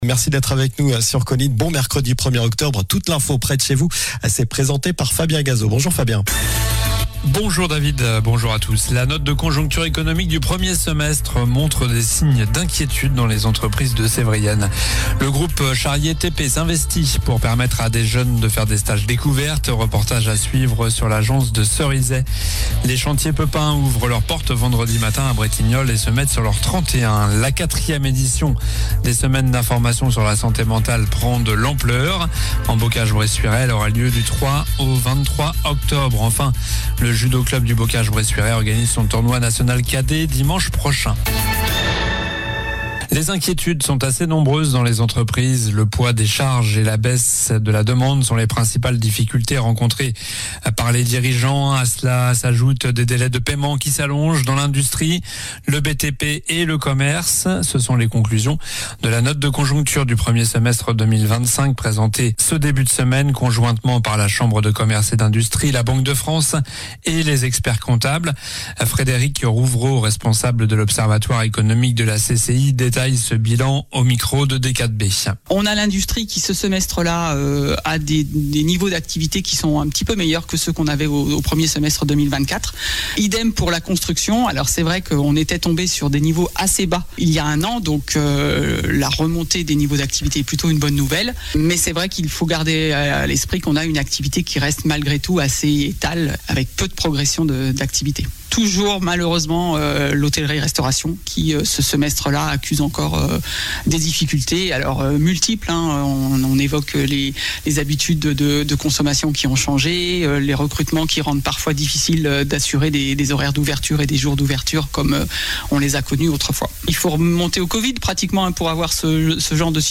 Journal du mercredi 1er octobre (midi)